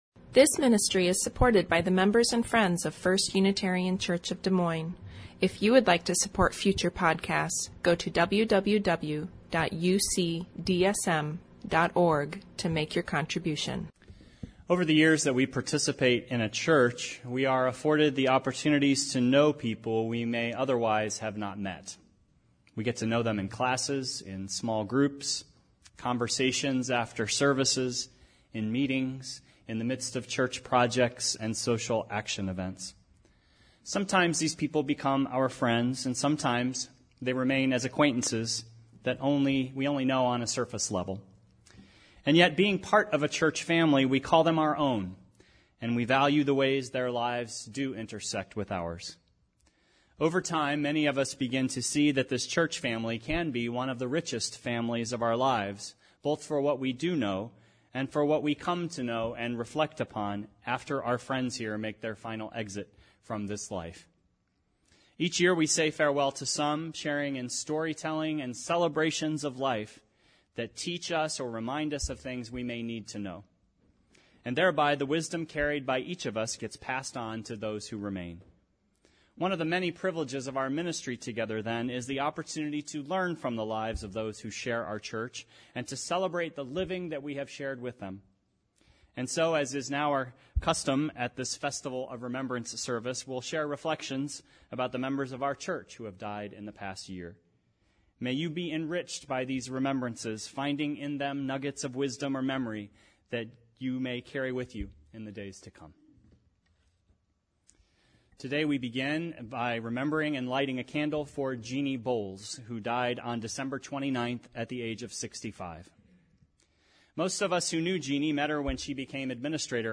We Remember Them: A Festival of Remembrance Service
A tender service of recognition, affirmation and celebration of this precious life we share .